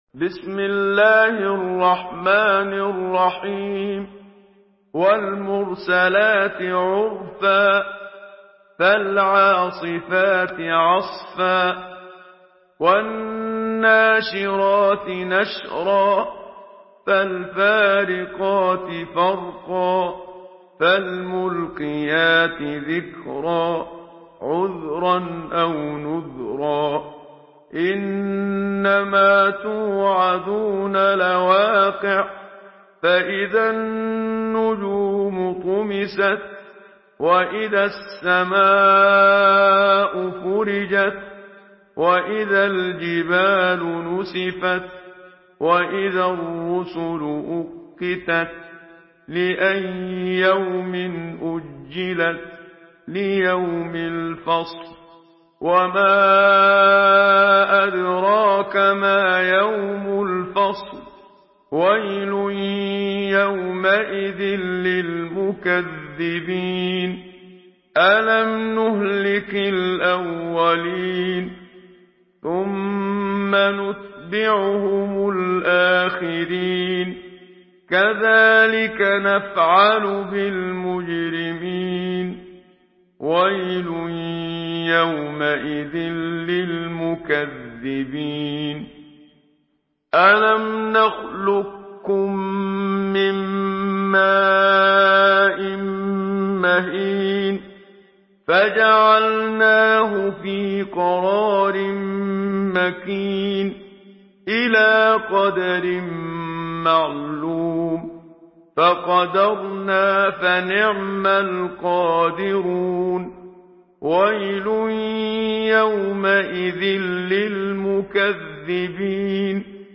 Surah Al-Mursalat MP3 by Muhammad Siddiq Minshawi in Hafs An Asim narration.
Murattal